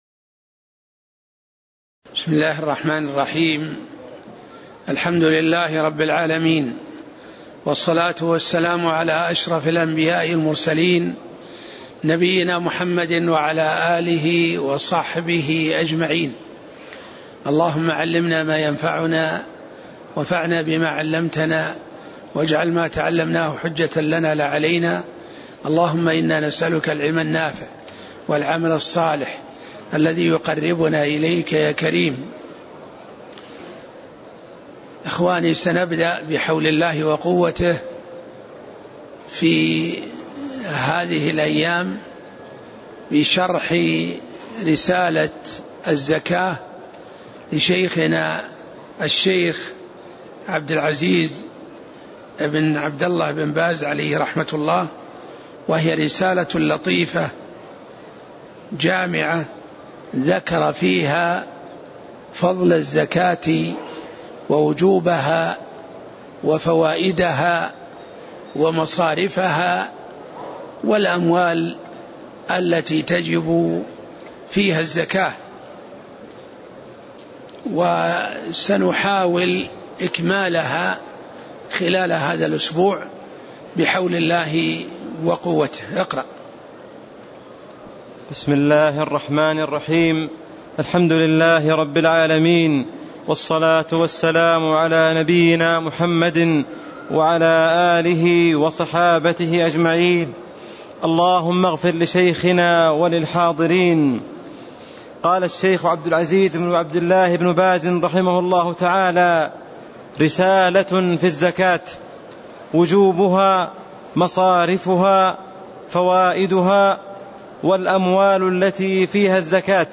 تاريخ النشر ١٩ شعبان ١٤٣٦ هـ المكان: المسجد النبوي الشيخ